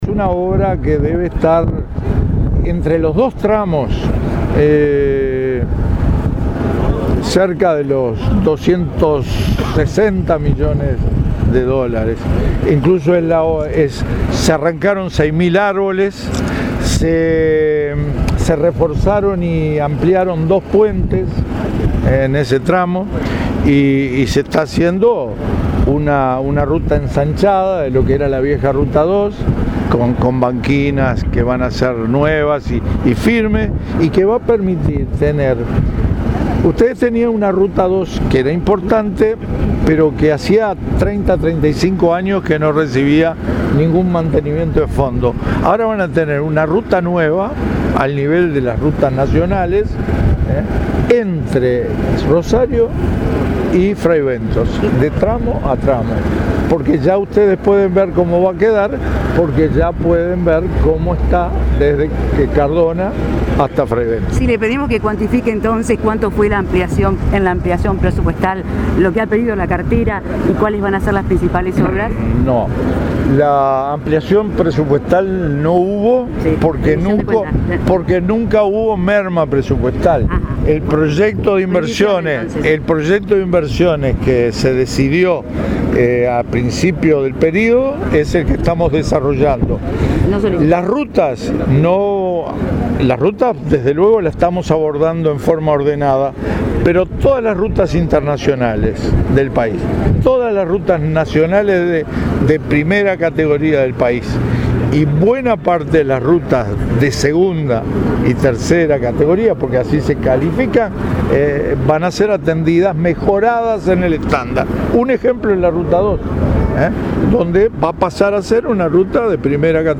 “Hay algunos que tienen ganas de que todo ande mal”, expresó el ministro de Transporte y Obras Públicas, Víctor Rossi, al inaugurar el segundo puente sobre el río Rosario en la ruta 1.